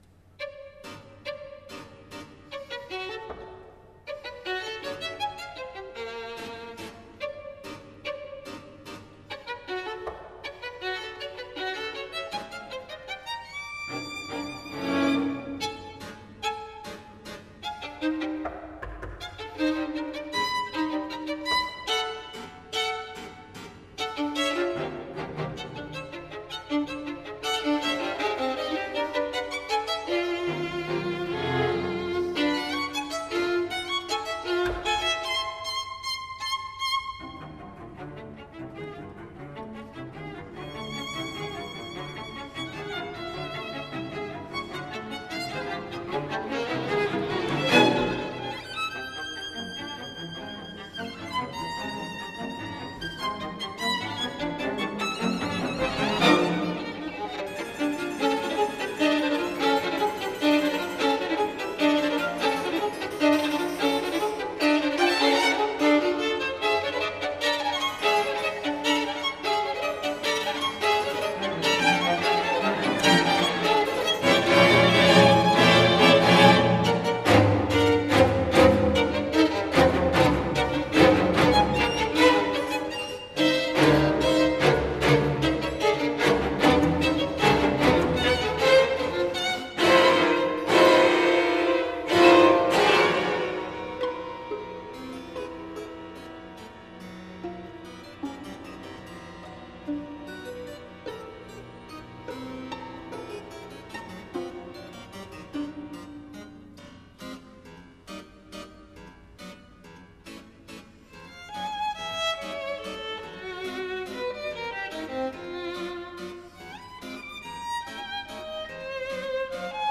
Schnittke, dalla Sonata n. 1 per violino - II Allegretto.mp3 — Laurea Triennale in Scienze e tecnologie della comunicazione